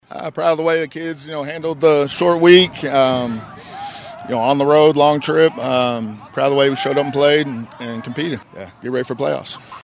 talked with KWON postgame